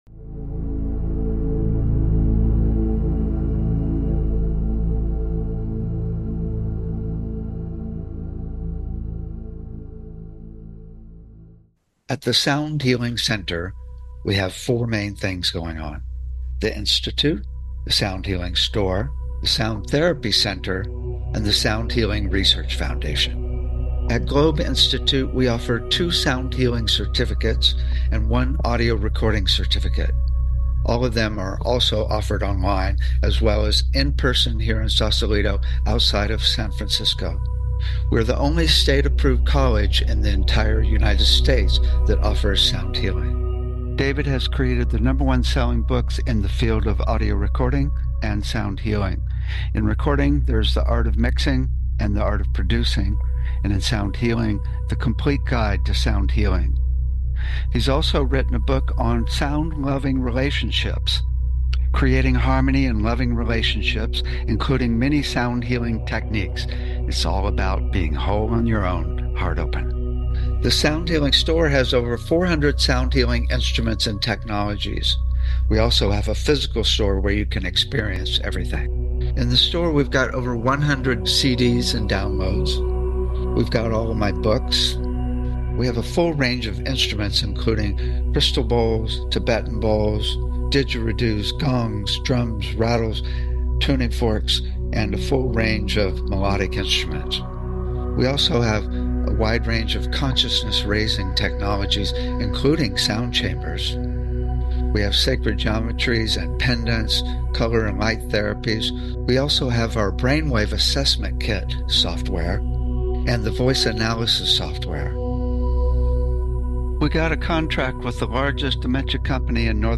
The show is a sound combination of discussion and experience including the following topics: Toning, Chanting and Overtone Singing - Root Frequency Entrainment - Sound to Improve Learning -Disabilities - Using Sound to Connect to Spirit - Tuning Fork Treatments - Voice Analysis Technologies - Chakra Balancing - Sound to Induce Desired States of Being - Tibetan/Crystal Bowl Massage - Electronic Nerve Stimulation - Water Sound Infusion Systems - Sound Visualization systems - Infratonics Holographic Sound - Scalar wave EESystem Drumming and Rhythm - HydroAcoustic Therapy - Neurophone Bio-Tuning - Sound Surgery - Cymatics Frequency based therapeutic devices - VibroAcoustic Sound Chairs and Tables /soundhealing#archives /soundhealing#showposts /soundhealing#upcoming /customshow/2574 /customshow/mrss/2574 /soundhealing#feedback BBS Station 1 Bi-Weekly Show -e- 7:00 pm CT 7:55 pm CT Saturday Education Energy Healing Sound Healing Love & Relationships Emotional Health and Freedom Mental Health Science Self Help Spiritual 0 Following Login to follow this talk show Sound Healing